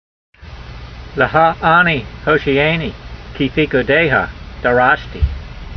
Sound (Psalm 119:94) Transliteration: le ' ha - a 'nee hoshee' ay nee , kee feekoo dey ha da' rash tee Vocabulary Guide: I belong to you — save me , for I have sought your precepts . Translation: I belong to you—save me, for I have sought your precepts.